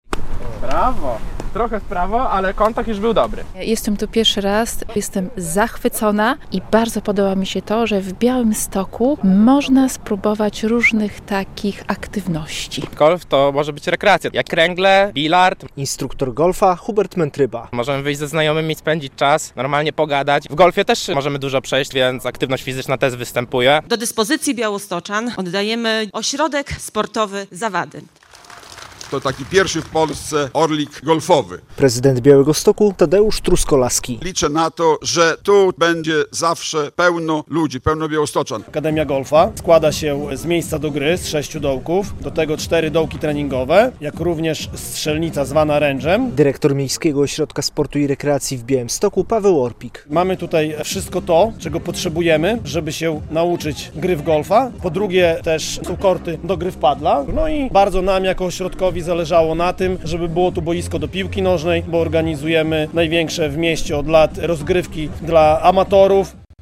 Otwarcie Ośrodka Sportowego Zawady w Białymstoku - relacja